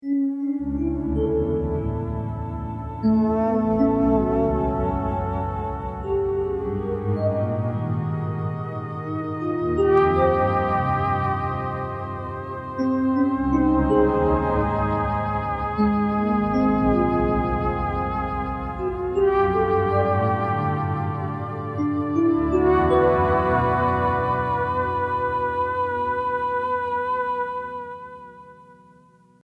Flute plus EP
Tags: Roland U-110 Synth sounds Roland U-110 sample Roland Roland U-110 sounds